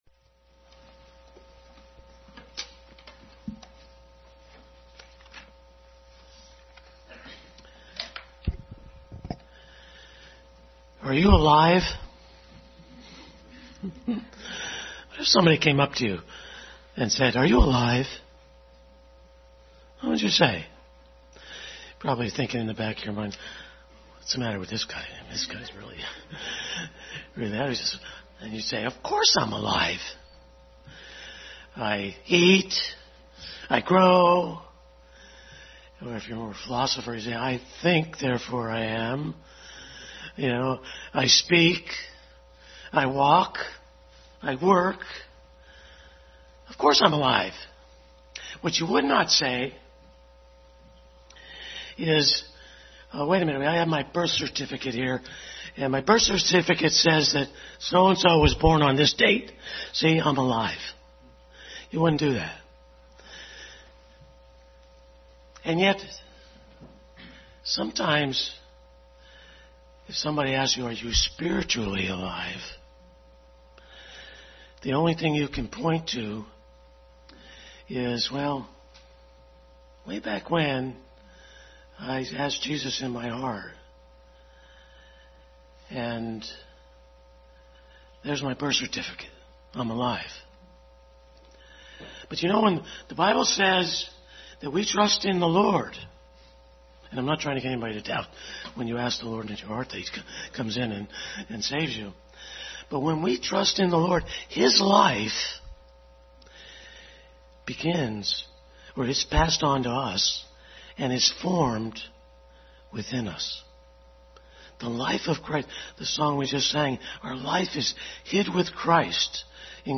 Bible Text: 1 Peter 2:2, Jeremiah 15:16, Psalm 119:97, Psalm 1:2, 2 Peter 3:18 | Family Bible Hour Message.